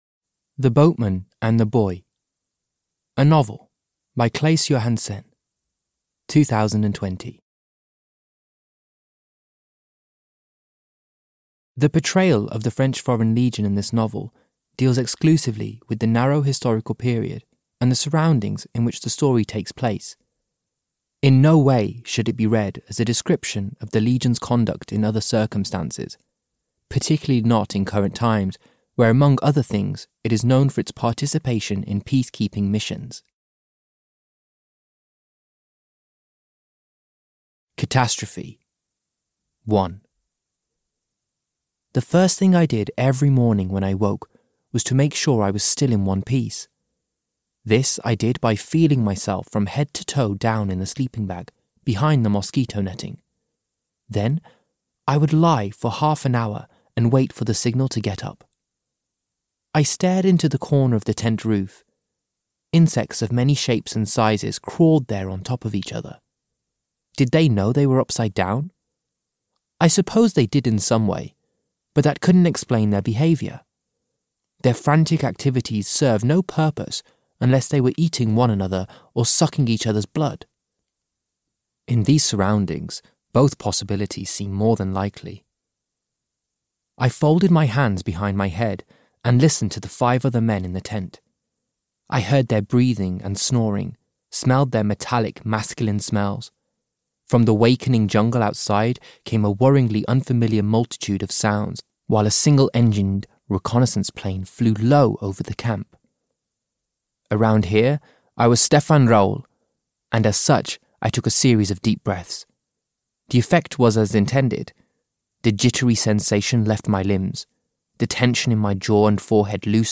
The Boatman and the Boy (EN) audiokniha
Ukázka z knihy